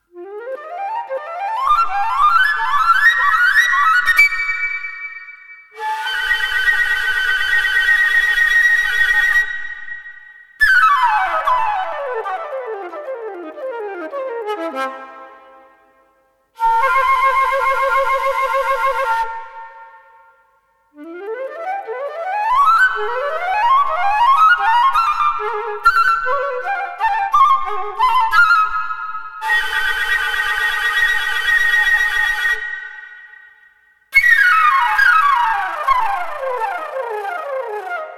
Камерно-инструментальная музыка